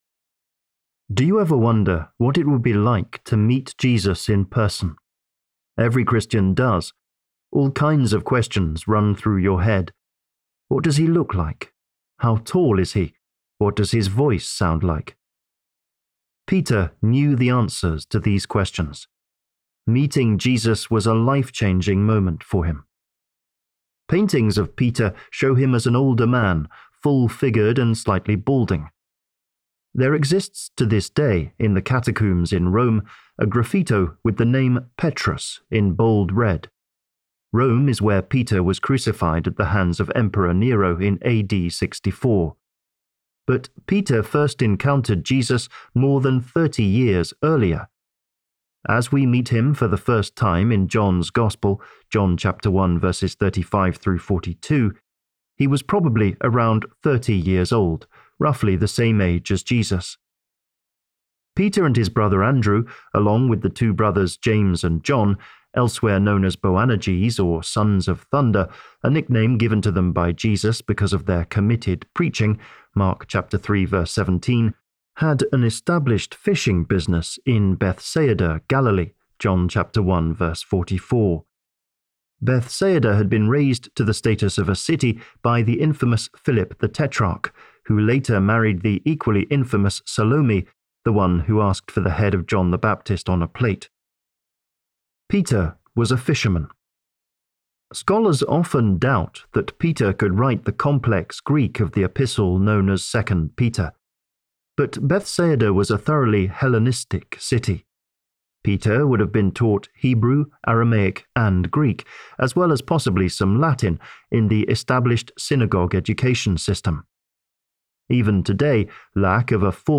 Audiobook Download